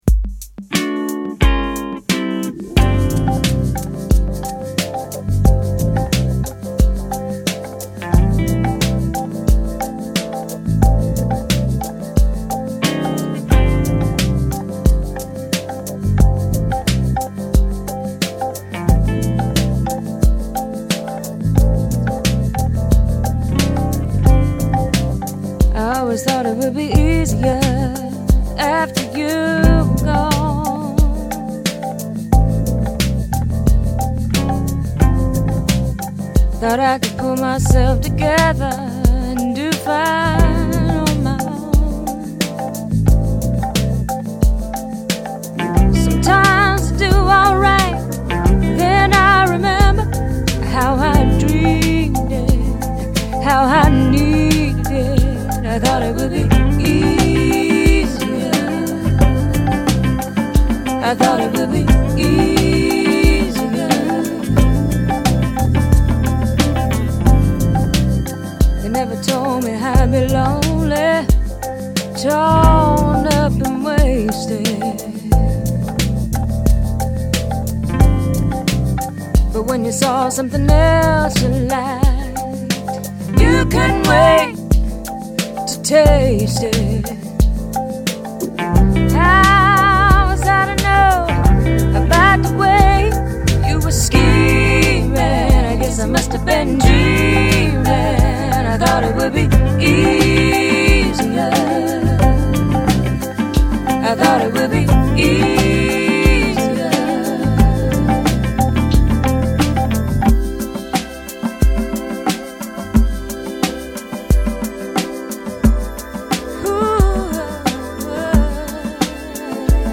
a bluesy, sexy collection